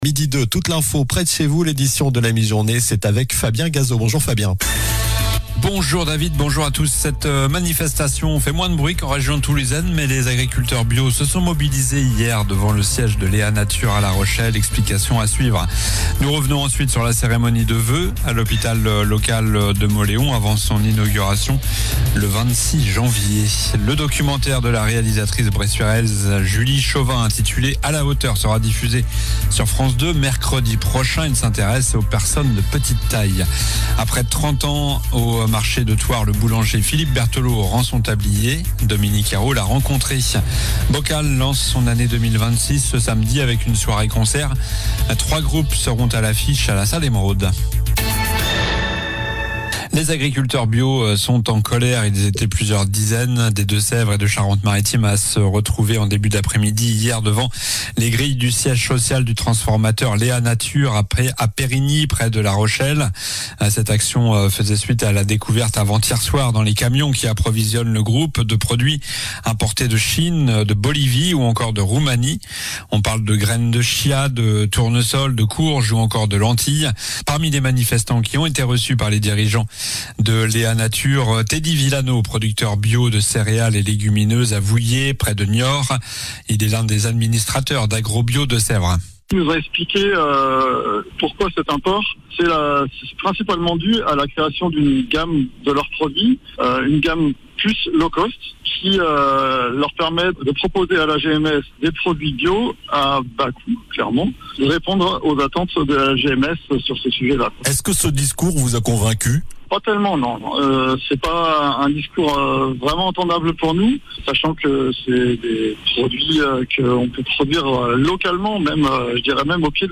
Journal du mercredi 14 janvier (midi)